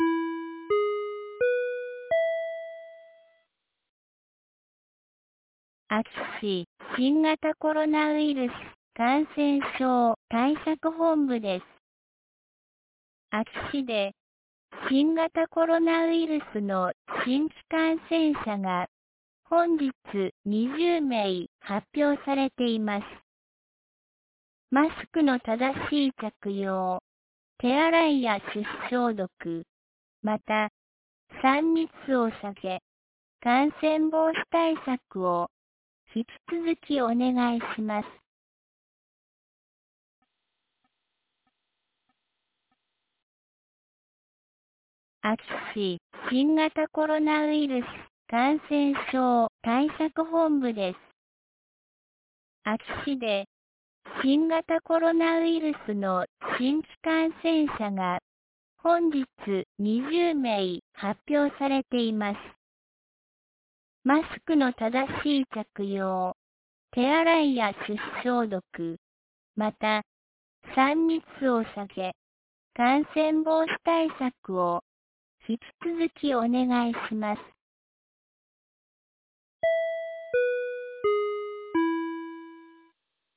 2022年08月12日 17時06分に、安芸市より全地区へ放送がありました。